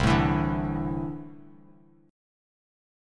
C6add9 chord